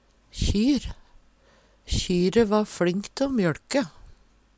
kjyr - Numedalsmål (en-US)